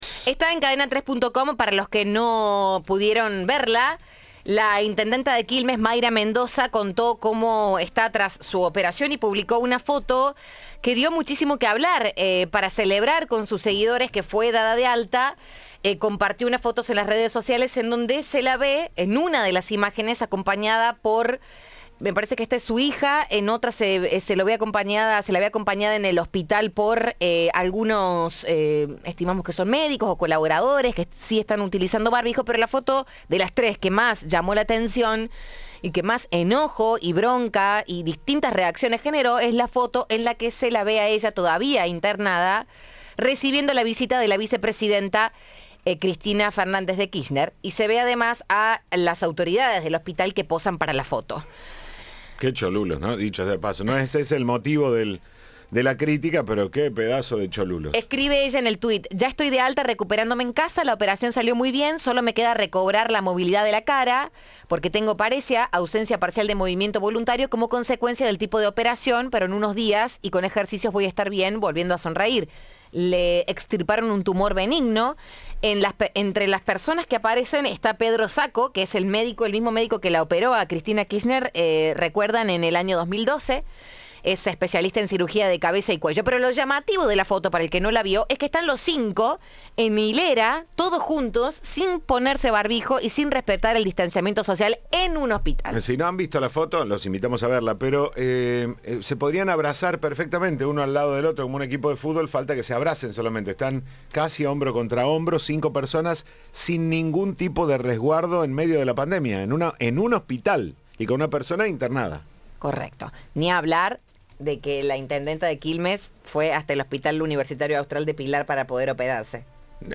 Sobre esto se manifestó en diálogo con Cadena 3